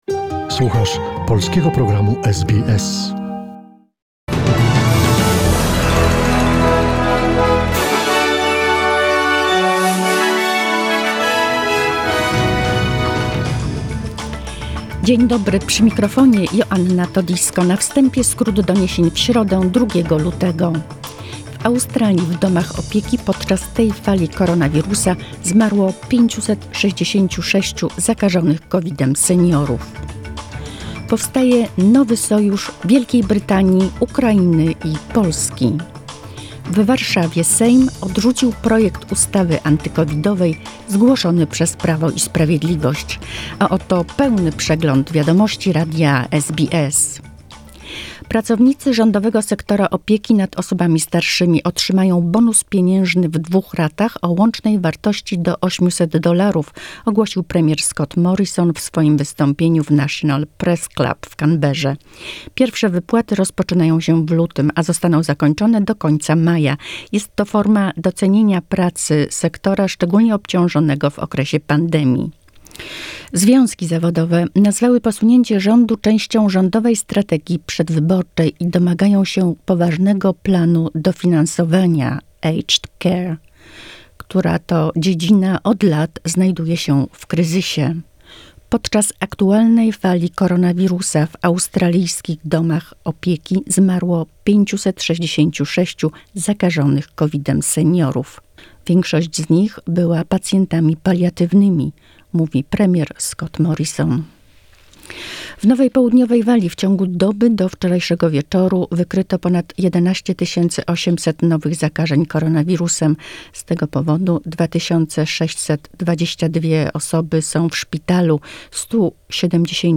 SBS News in Polish, 2 lutego 2022